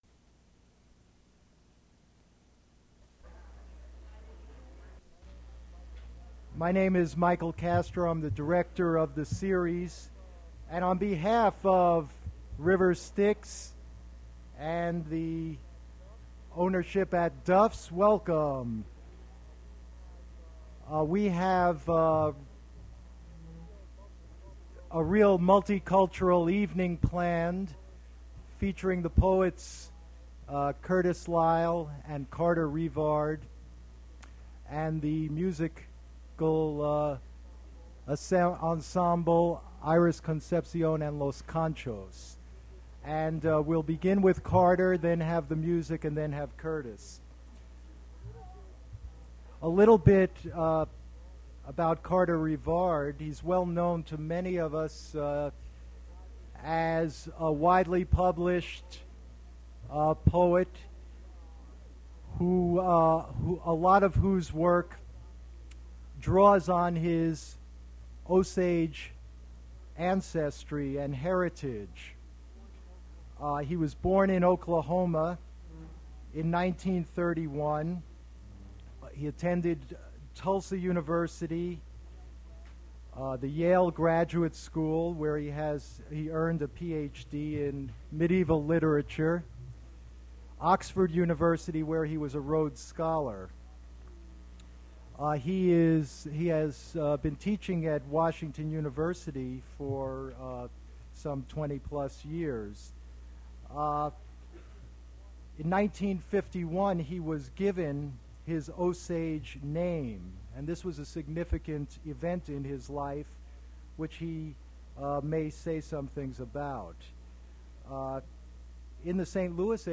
Attributes Attribute Name Values Description Carter Revard poetry reading at Duff's Restaurant.
Cut music after Revard's reading